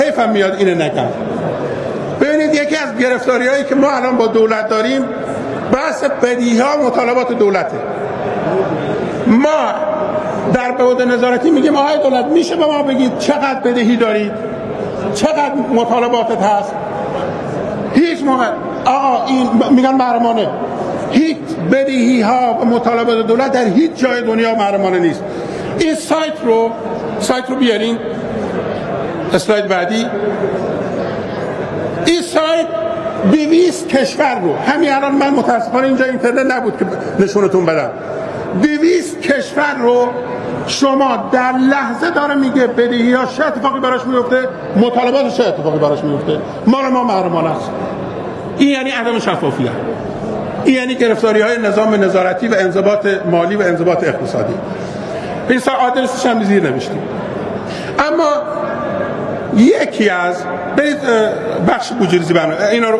به گزارش پایگاه عدالت خواهان" روز دوشنبه ۲۲ آبان، صحن علنی مجلس شورای اسلامی در بهارستان میزبان «محمد باقر نوبخت» رئیس سازمان برنامه و بودجه و «عادل آذر» رئیس دیوان محاسبات بود. موضوع جلسه ارائه شاخص‌های بودجه‌ریزی عملیاتی در بودجه سال ۱۳۹۷ کل کشور بود.